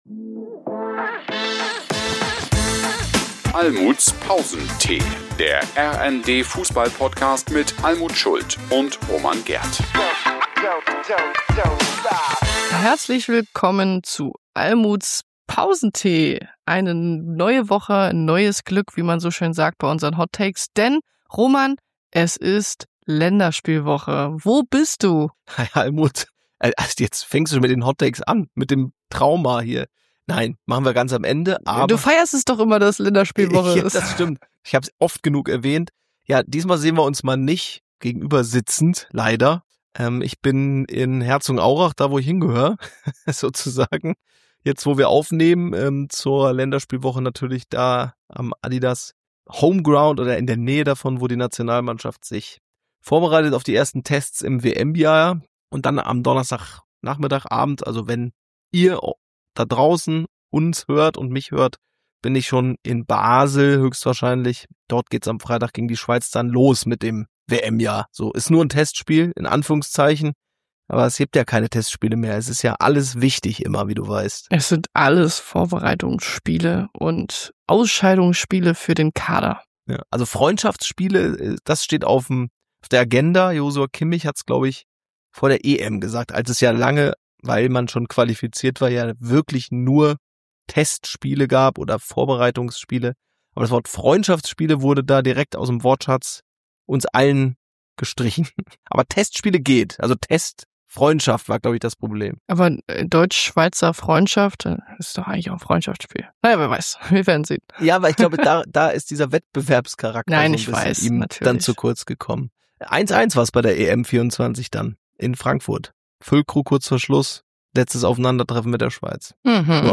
Die Welttorhüterin von 2014 und Olympiasiegerin 2016 mit Deutschlands Fußballerinnen Almuth Schult stellt hier ihr Thema oder ihre These der Woche vor.
Und dann wird diskutiert.